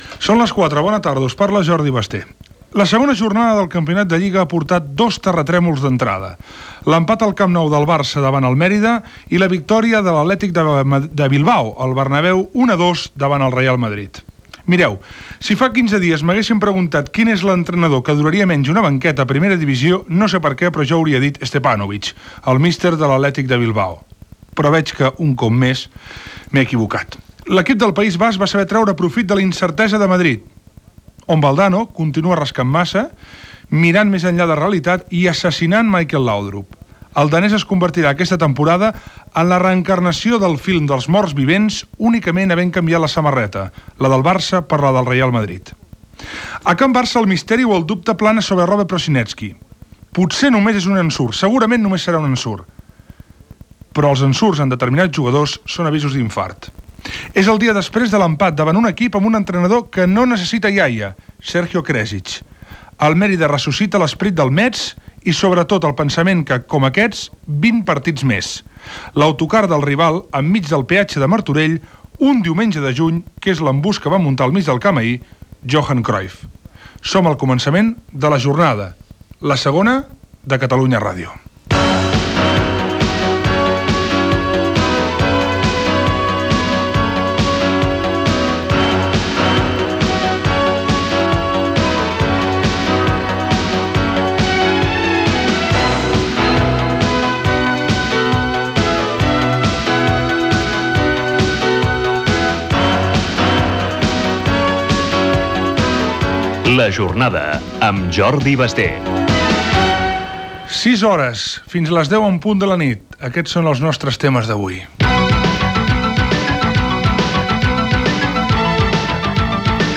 Esportiu